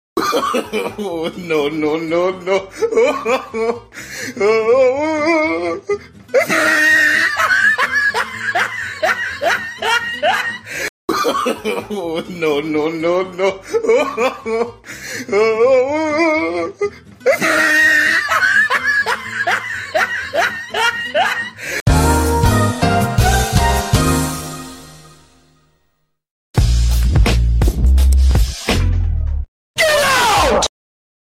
wet farts Sound Button: Unblocked Meme Soundboard